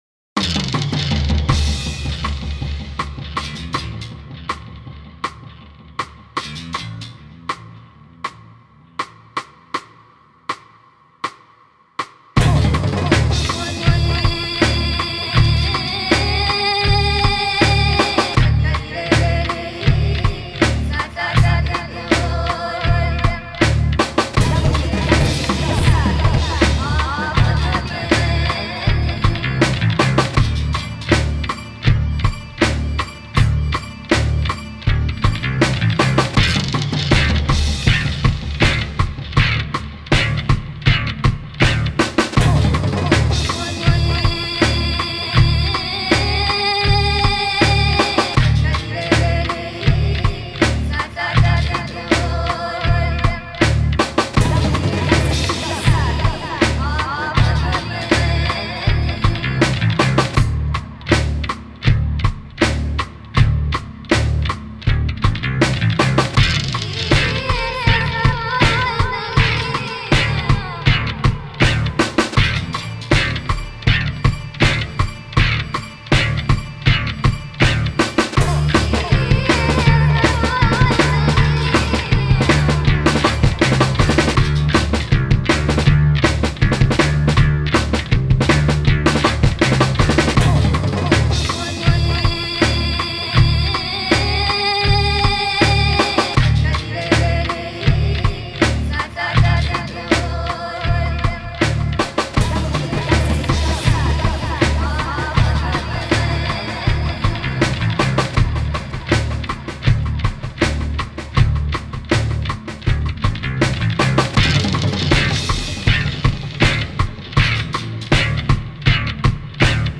Categoria Original Soundtrack
Vocalist No